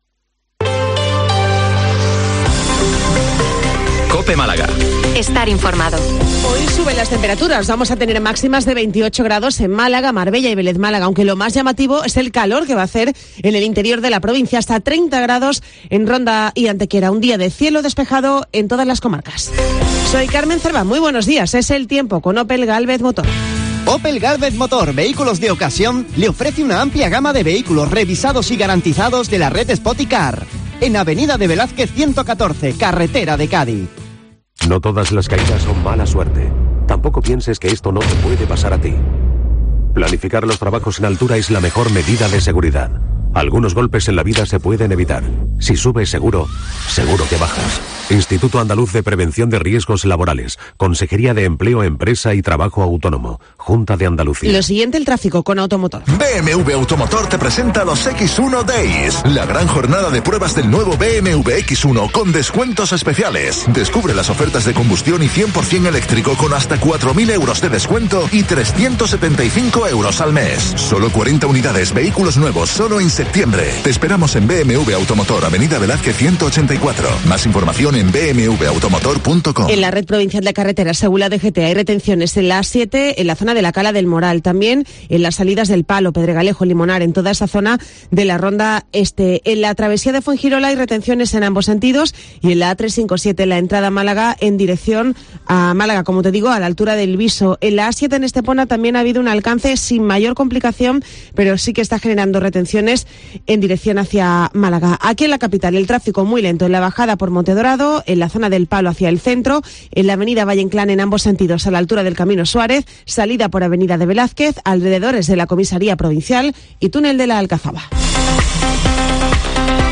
Informativo 08:24 Málaga - 280923